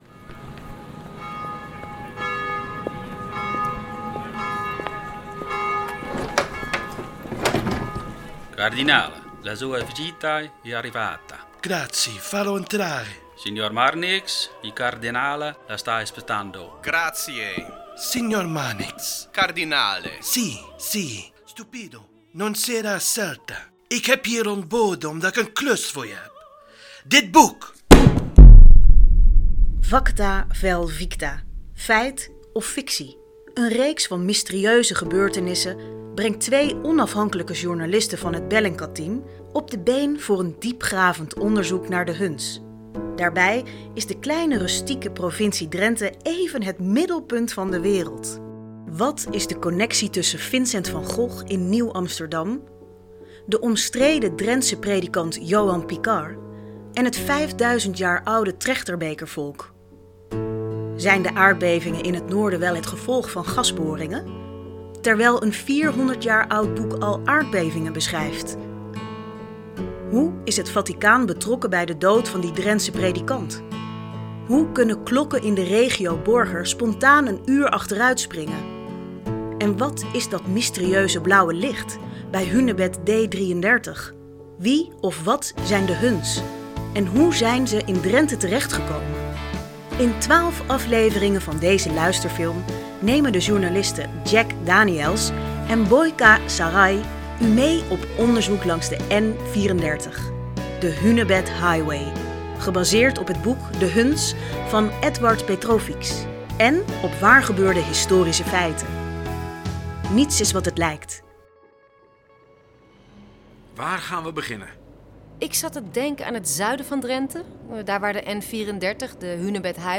Het wordt spannend… u gaat exclusief luisteren naar de eerste aflevering van de luisterfilm De Huns.